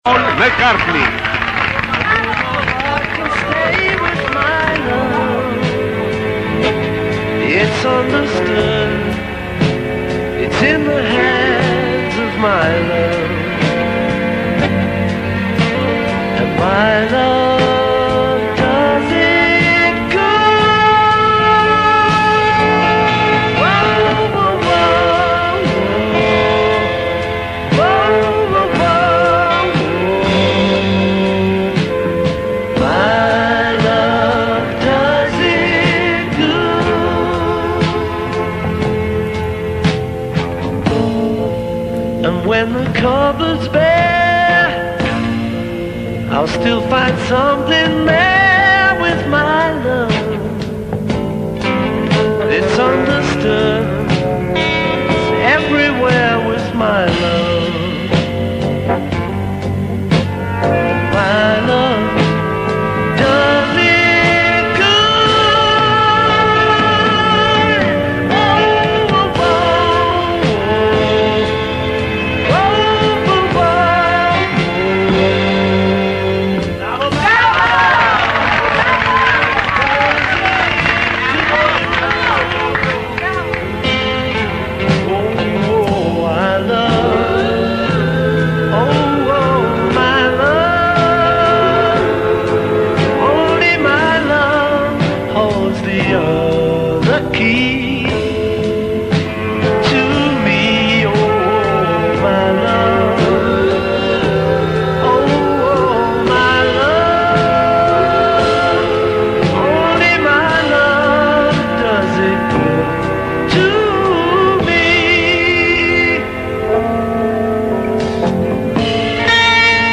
I brani musicali sono sfumati per le solite esigenze di tutela del copyright.
La canzone che viene presentata tra il tripudio del pubblico in sala (non sappiamo se vero o registrato) è la non disprezzabile ma neanche memorabile My Love di Paul McCartney nel suo periodo Eastman.